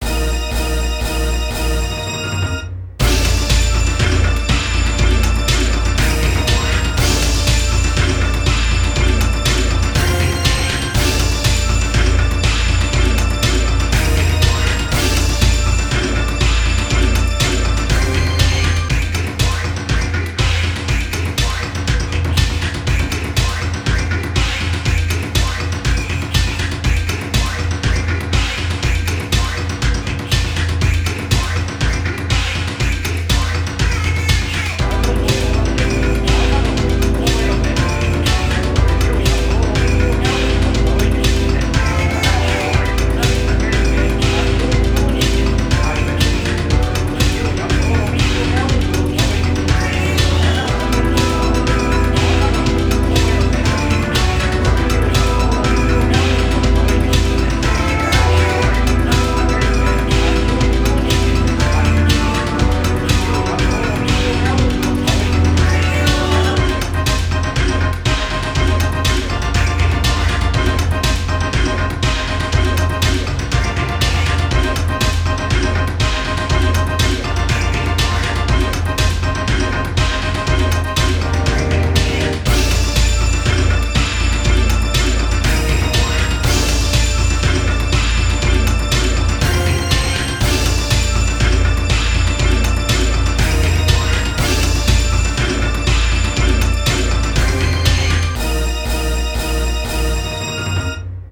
BPM120
Audio QualityPerfect (High Quality)